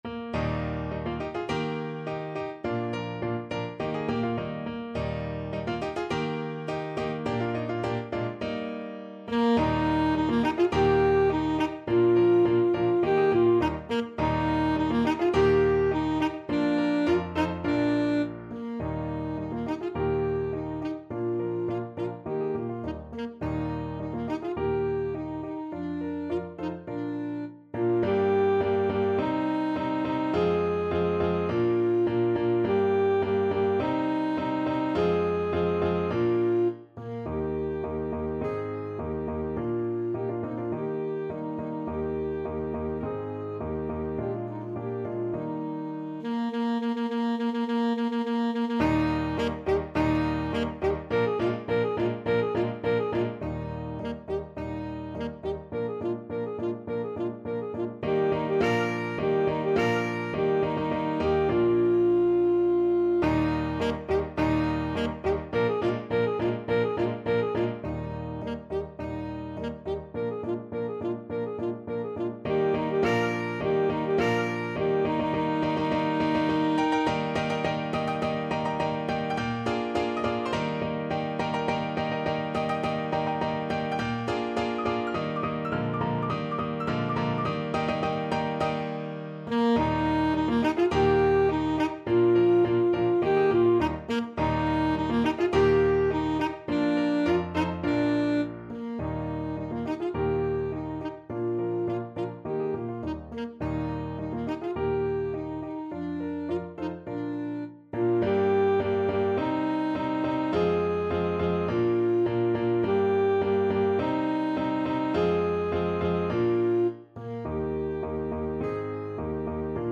Alto Saxophone
~ = 100 Molto vivace =104
2/4 (View more 2/4 Music)
Classical (View more Classical Saxophone Music)